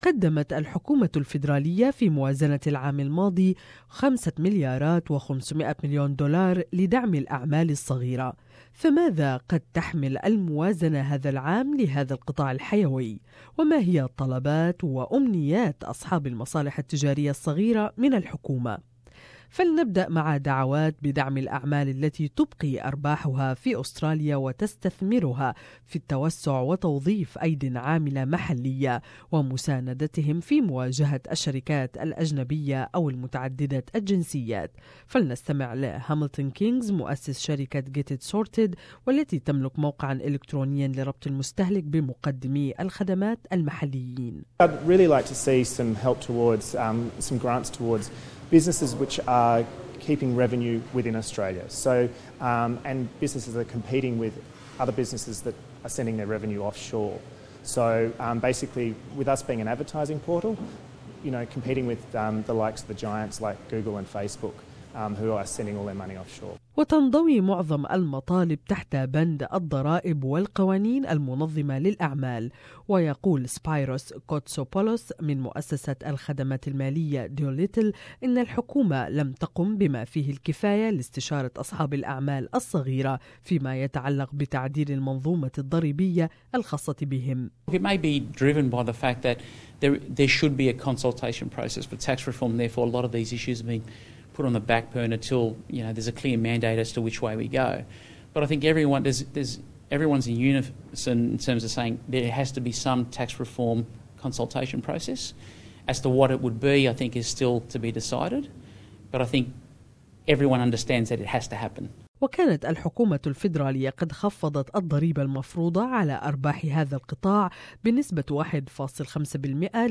ما هي مطالب و امنيات اصحاب المصالح التجارية الصغيرة من الموازنة المقبلة. الاجابة في هذا التقرير الاخباري